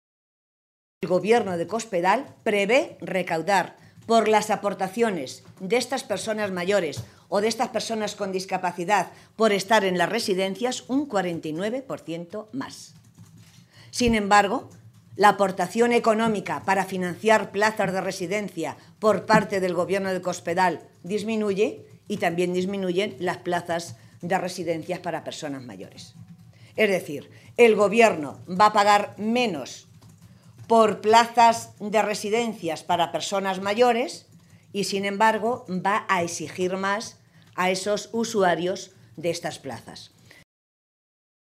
Matilde Valentín, Presidenta del PSOE de Castilla-La Mancha
Cortes de audio de la rueda de prensa